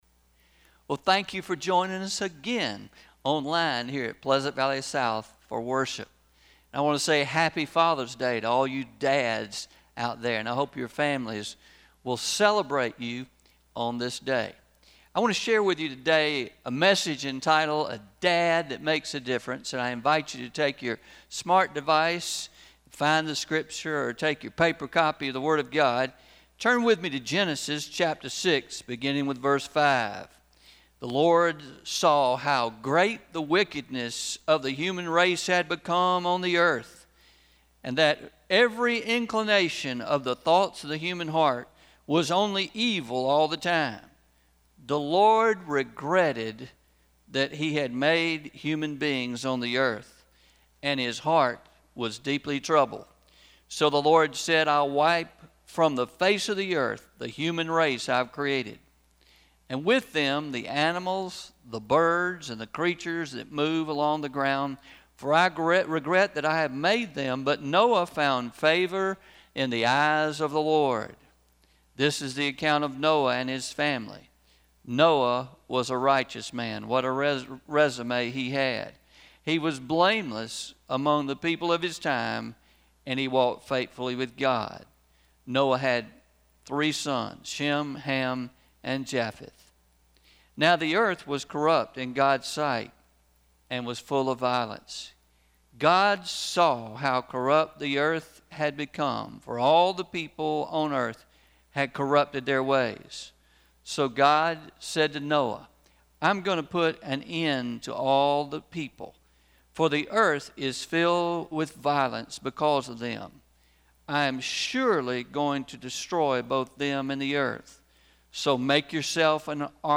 06-21-20am Sermon – Dads who make a Difference – Traditional